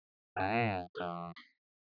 2.5 The wefts and clicks added together, attempting to reproduce the speech without the noise. The clicks (corresponding to stop releases in the original sound) do not stream well with the voiced speech. 2.6 All the elements to reconstruct the original.